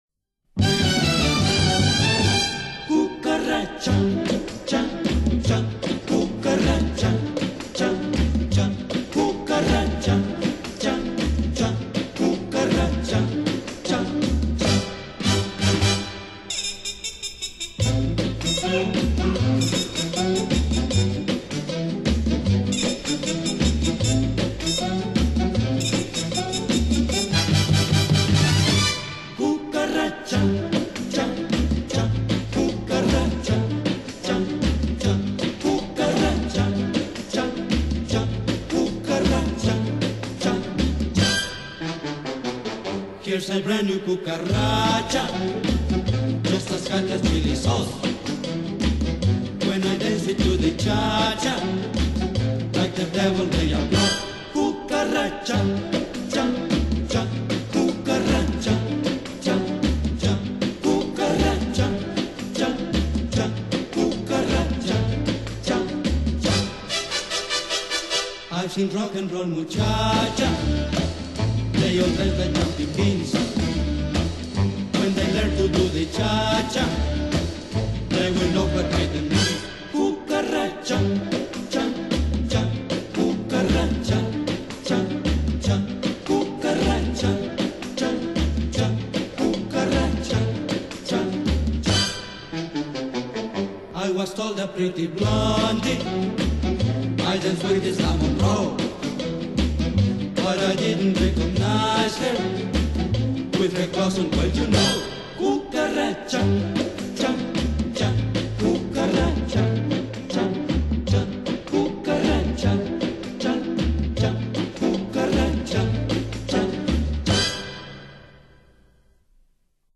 Genre: Latin, instrumental, orchestra